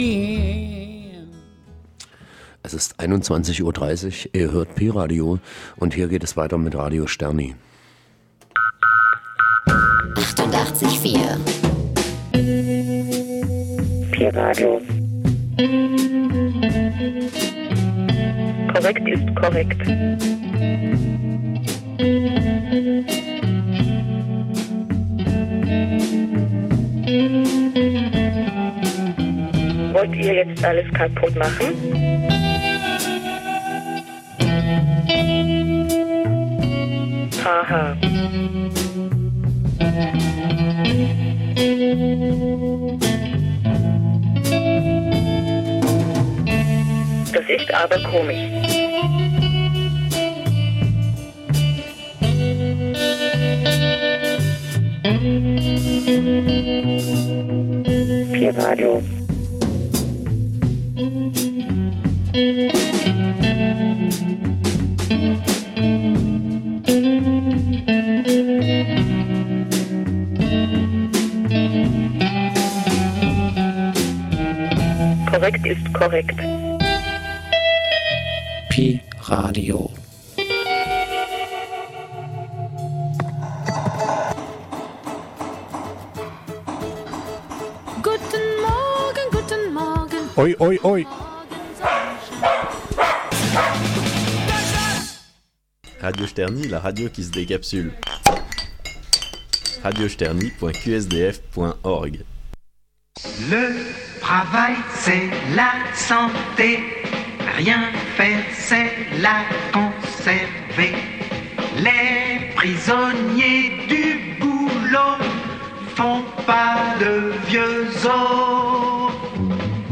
Eine musikalische Sendung über Nicht-Arbeit. Eine Vorstellung verschiedener französischen Lieder gegen Arbeit und für das Faulenzen...